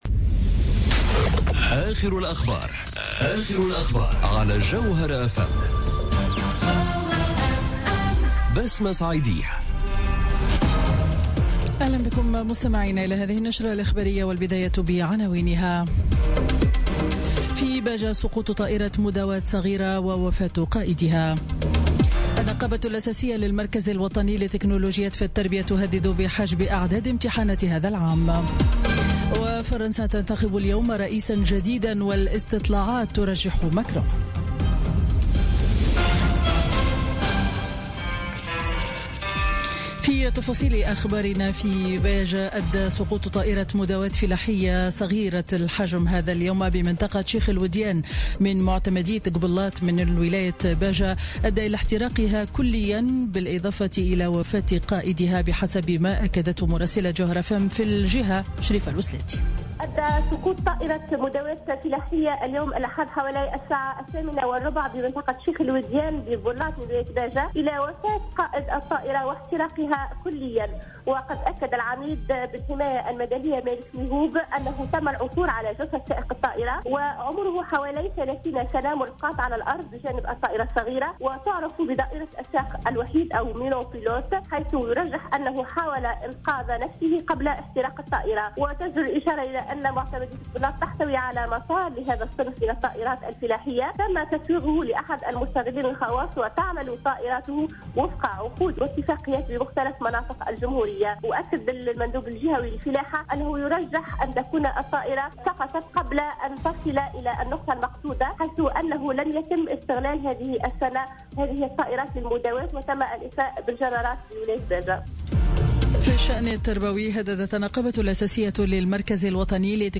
نشرة أخبار منتصف النهار ليوم الأحد 7 ماي 2017